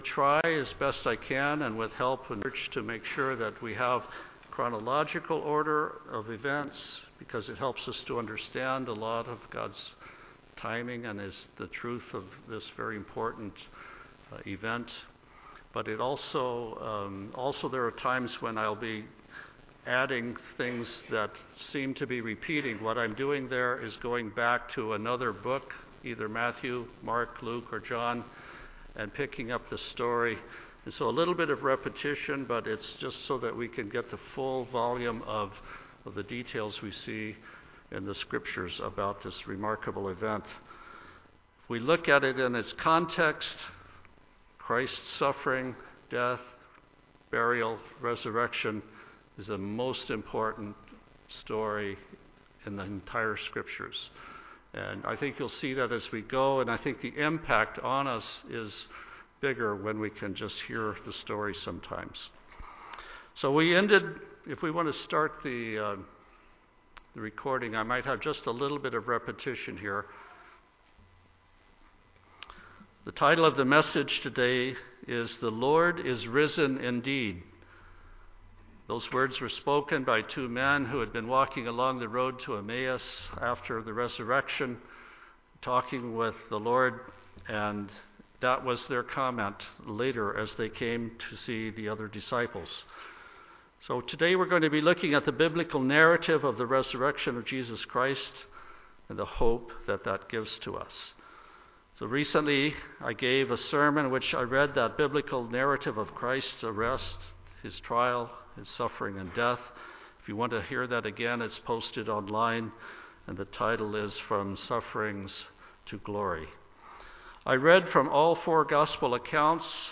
To keep the story flow intact, the reading is without reference to each of the chapters and verses.
Sermons
Given in Tacoma, WA